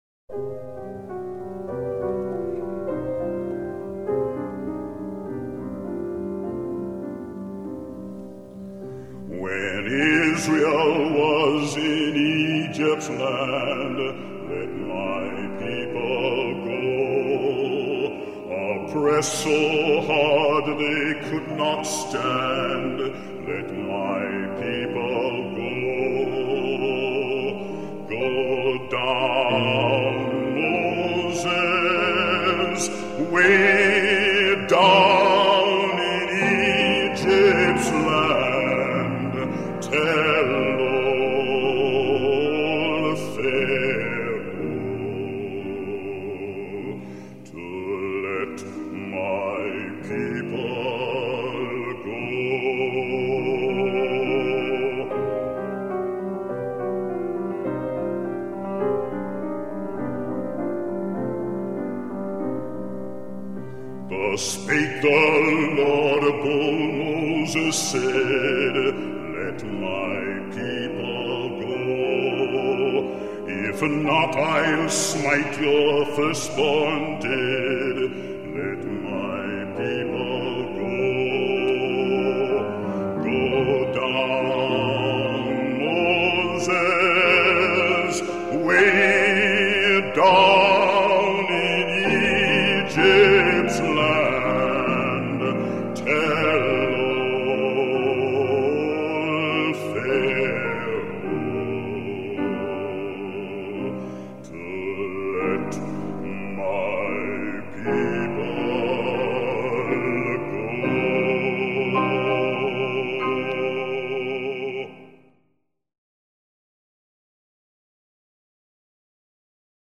Кто слышал только Армстронга - настоятельно рекомендую послушать второй вариант, он не джазовый, а выглядит, натурально, как песня протеста, и такая трактовка вопля Let My People Go натурально сносит крышу.